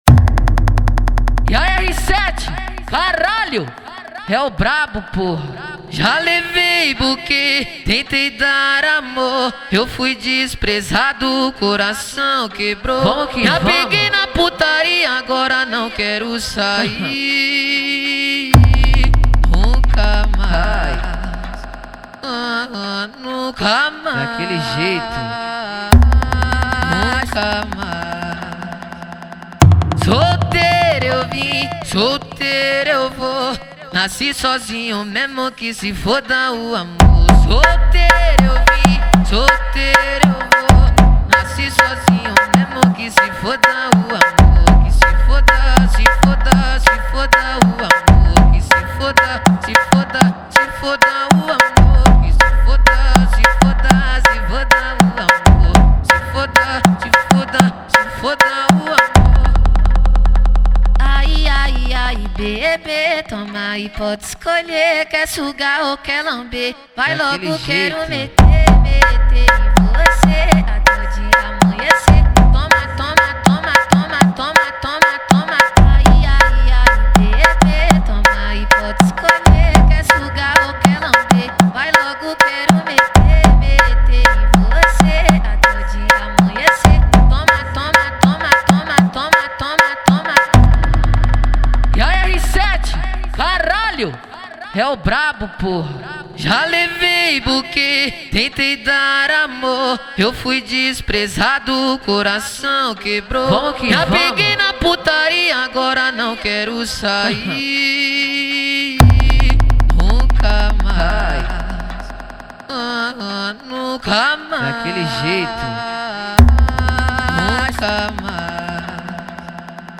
2024-05-31 00:01:09 Gênero: Funk Views